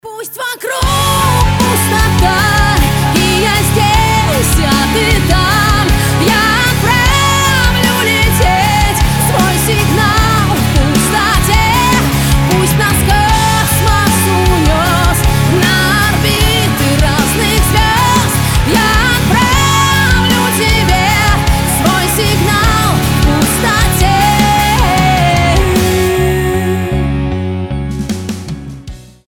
громкие
Alternative Rock
красивый вокал
сильный голос